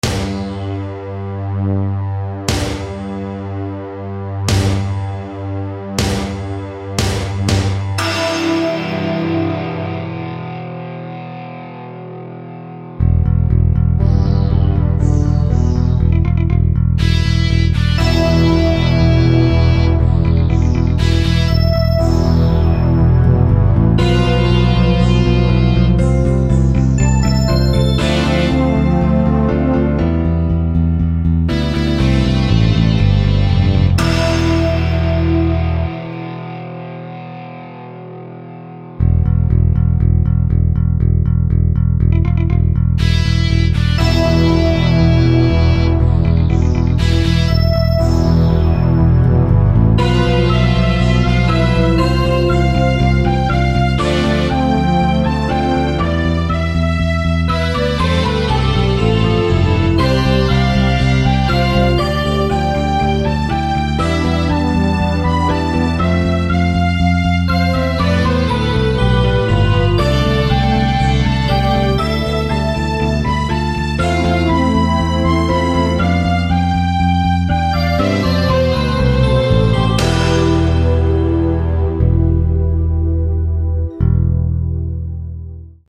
instrumental tracks